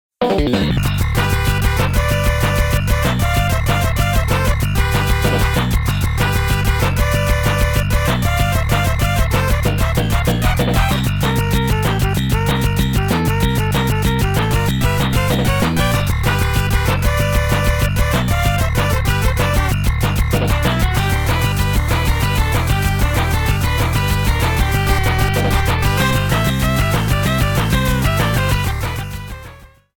Fair use music sample
30 seconds and fadeout You cannot overwrite this file.